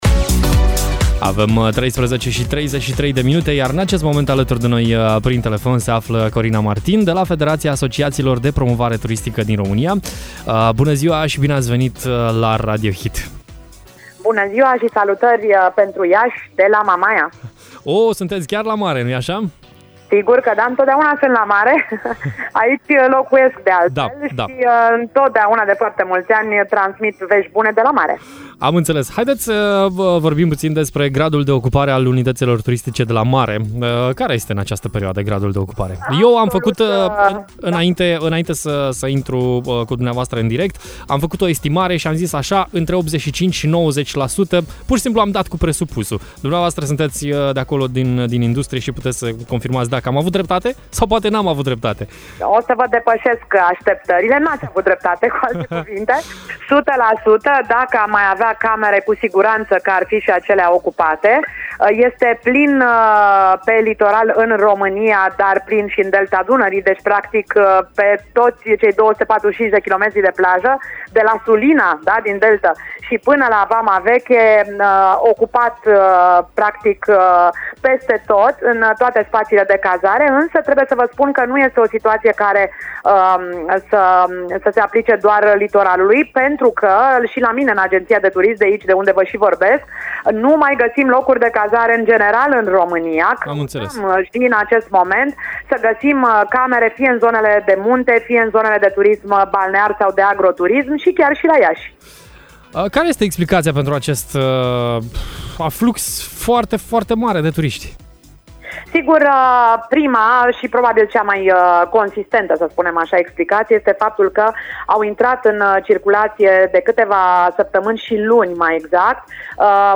Grad de ocupare 100% a hotelurilor și pensiunilor de la mare și de la munte. Ce poate face Iașul pentru a crește din punct de vedere turistic? A răspuns la această întrebare în direct la Radio Hit